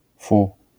wymowa:
IPA[fu], AS[fu]